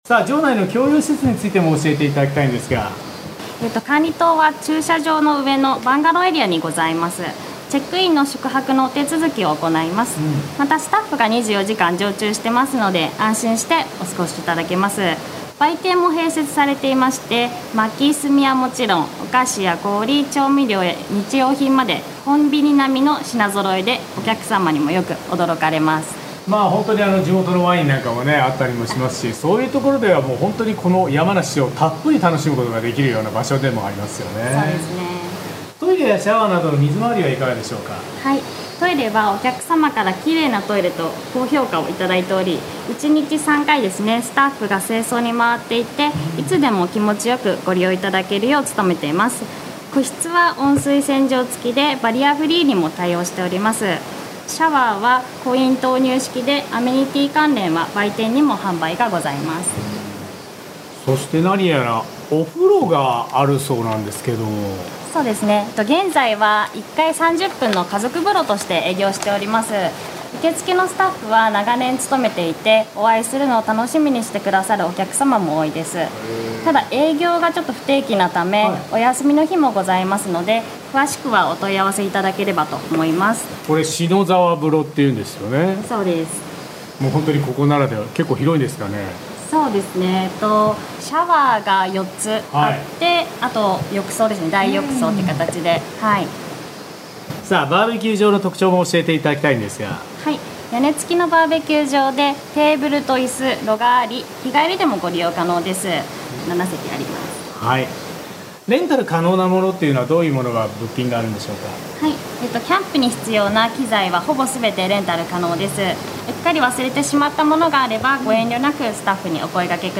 毎週土曜午前11時から生放送。